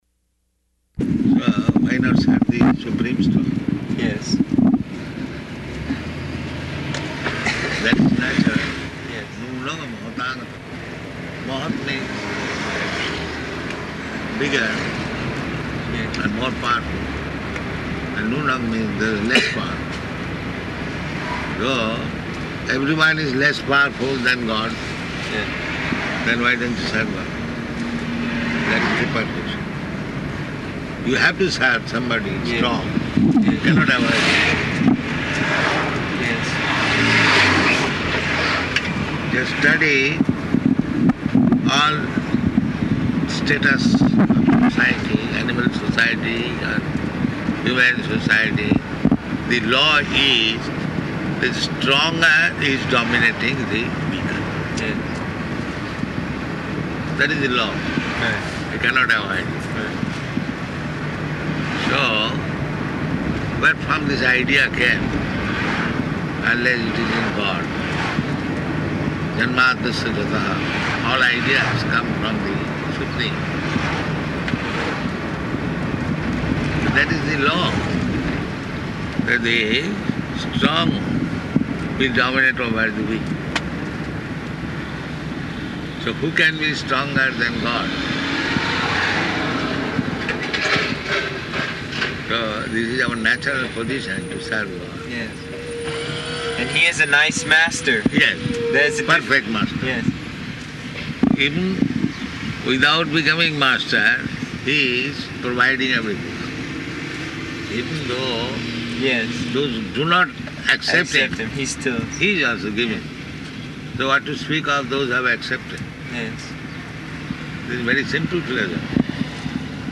Car Conversation
Location: Honolulu